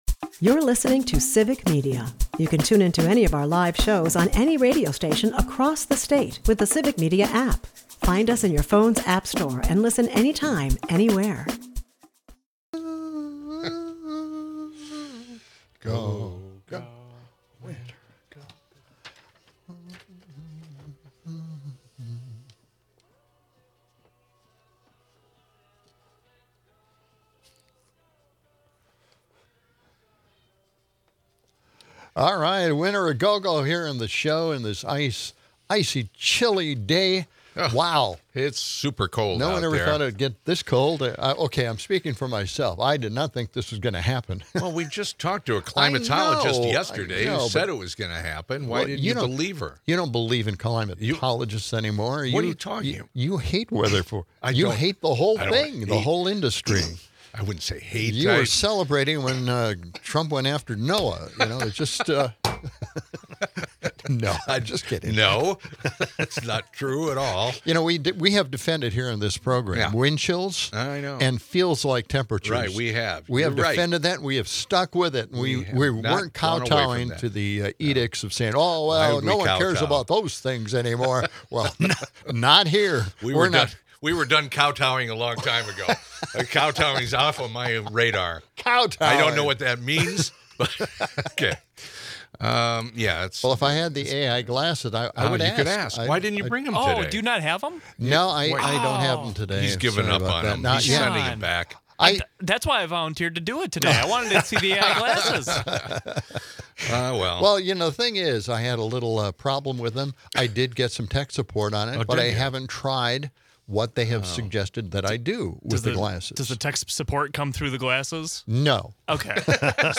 Spirited callers weigh in, sparking debate over universal healthcare and the inadequacies of the current system